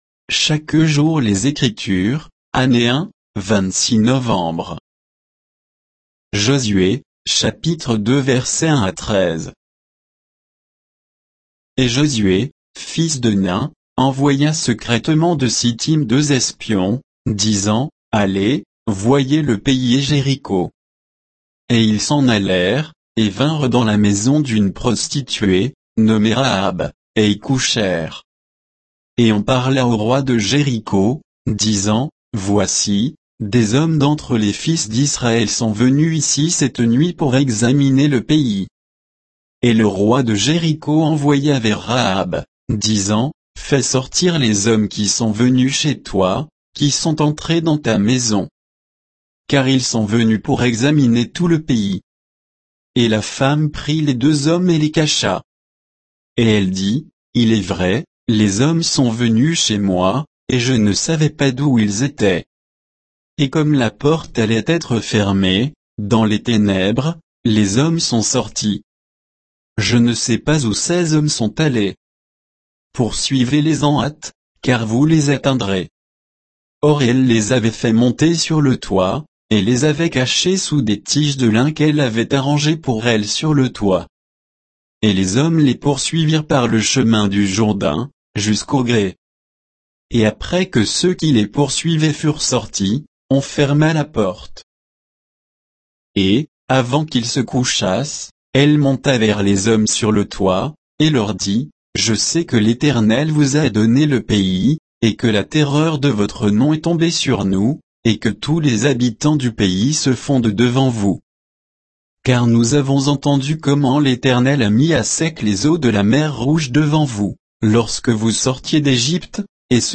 Méditation quoditienne de Chaque jour les Écritures sur Josué 2, 1 à 13